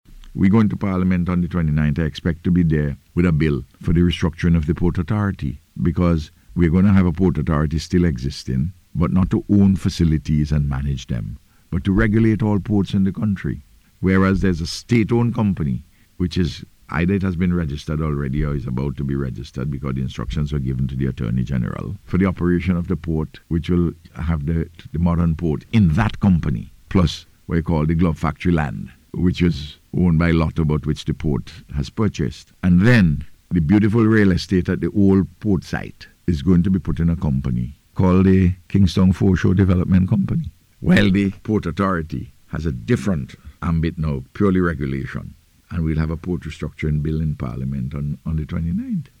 Speaking on NBC’s Face to Face Program on Wednesday, the Prime Minister said a Bill for the restructuring of the Port Authority will be taken to Parliament next week.